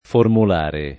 vai all'elenco alfabetico delle voci ingrandisci il carattere 100% rimpicciolisci il carattere stampa invia tramite posta elettronica codividi su Facebook formulare [ formul # re ] (oggi raro formolare [ formol # re ]) agg.